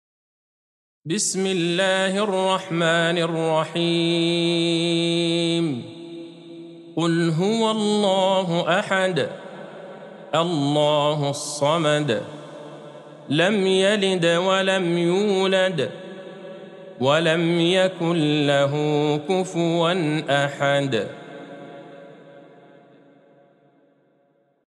سورة الإخلاص Surat Al-Ikhlas | مصحف المقارئ القرآنية > الختمة المرتلة ( مصحف المقارئ القرآنية) للشيخ عبدالله البعيجان > المصحف - تلاوات الحرمين